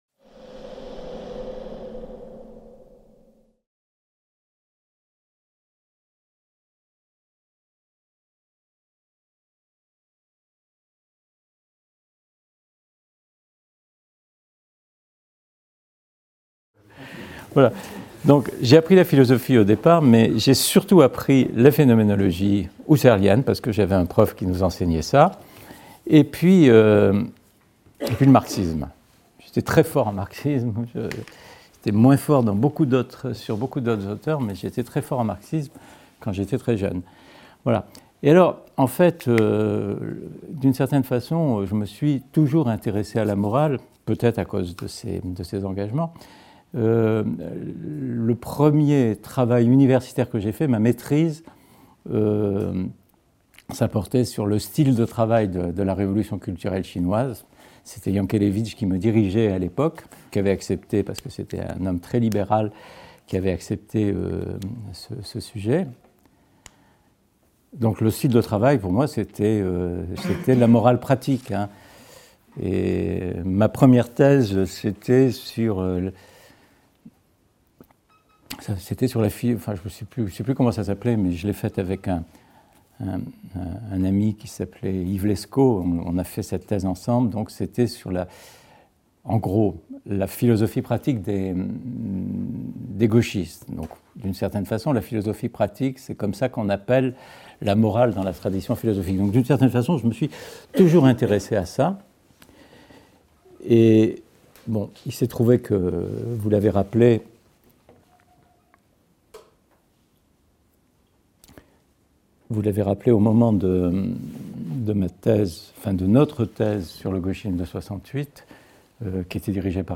(MRSH, Salle des Actes et des Thèses)